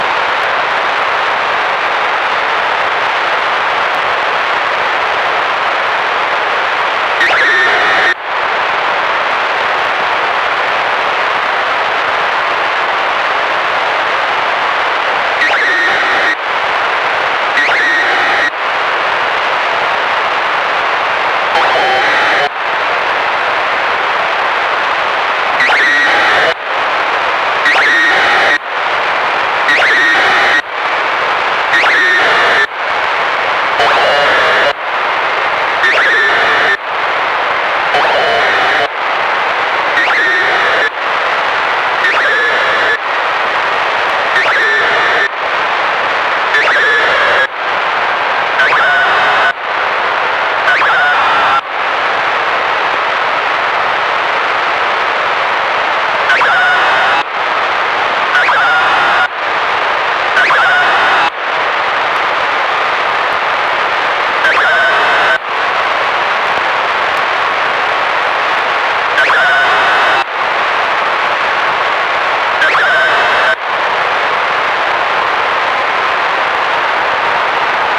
Бортовой радиомаяк станции, работающий в радиолюбительских УКВ диапазонах частот, должен излучать зондирующие сигналы, представляющие собой метки времени.
Я сегодня уже принимал сигналы -- вполне неплохо ловятся даже в центре Минска.